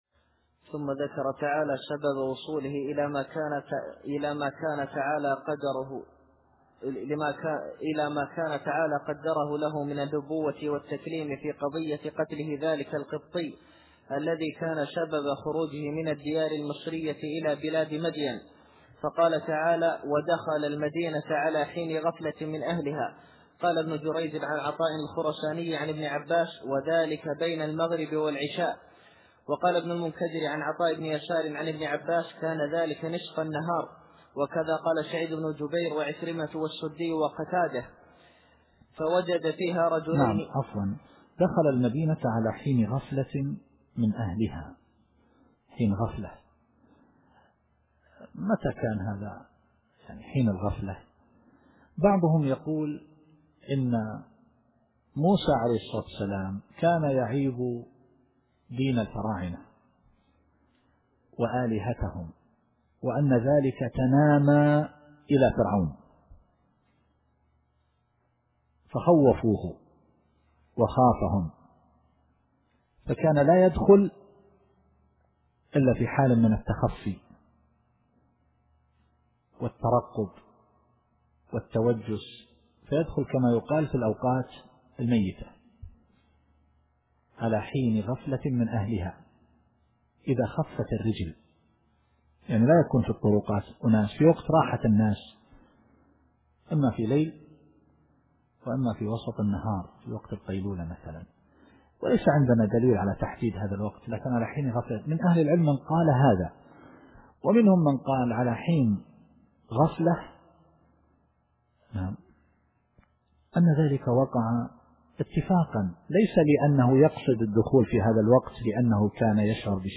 التفسير الصوتي [القصص / 15]